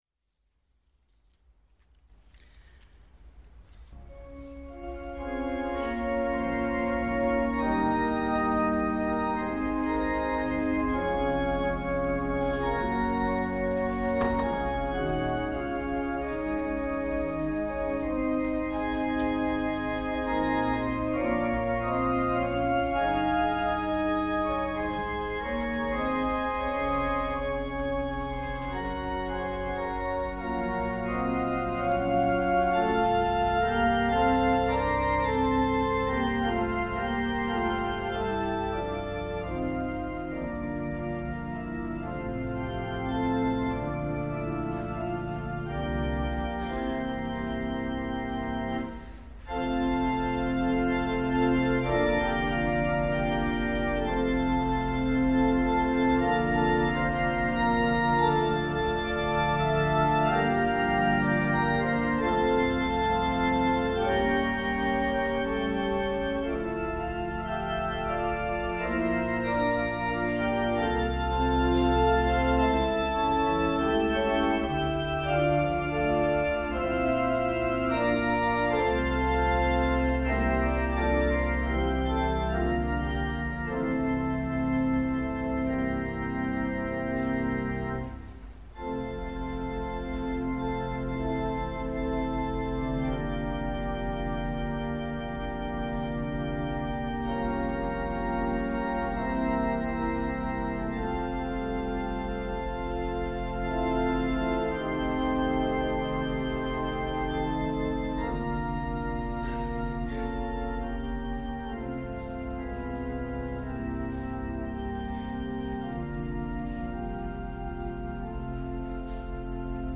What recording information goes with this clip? The services we post here were preached the previous week.